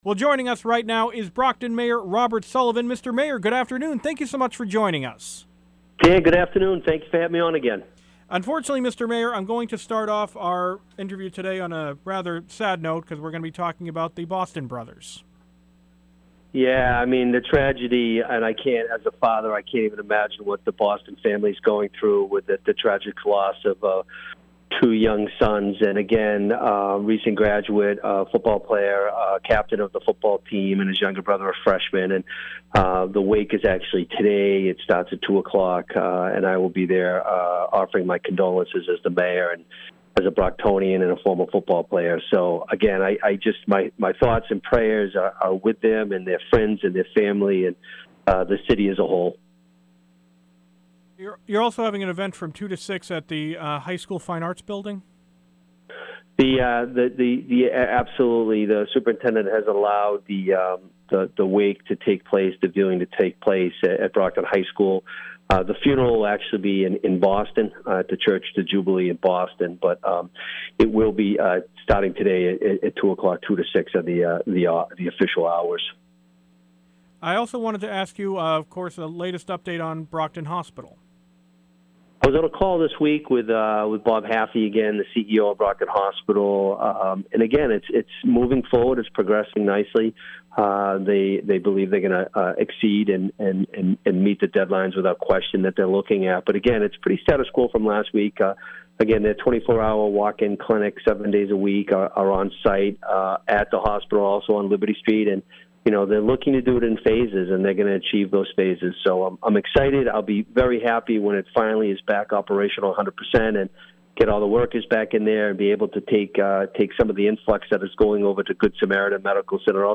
Brockton Mayor Robert Sullivan speaks